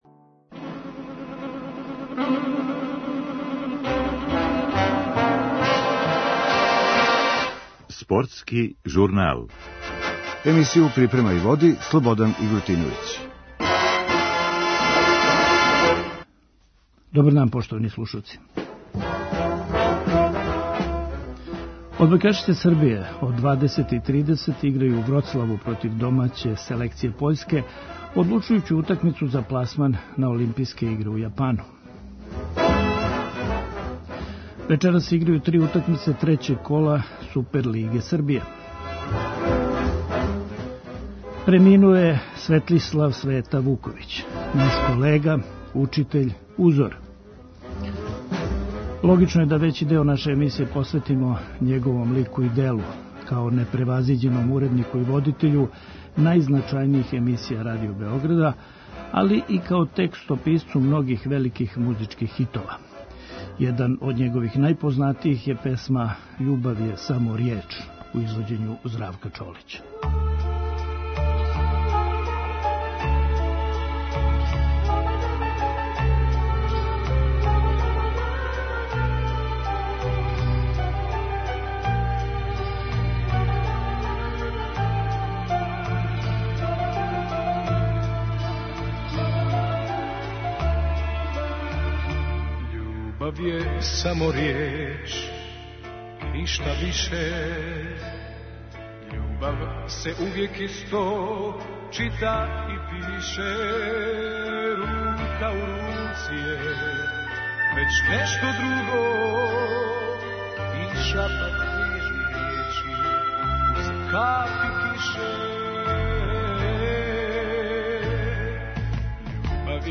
Чућемо реаговања колега и спортиста, а чућемо и аутентичне снимке из нашег програма.